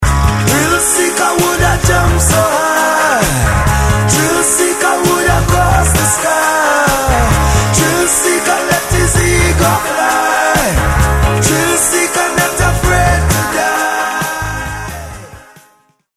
With an Eclectic Roots Rock Reggae!